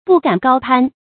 不敢高攀 注音： ㄅㄨˋ ㄍㄢˇ ㄍㄠ ㄆㄢ 讀音讀法： 意思解釋： 攀：比喻結交依附他人。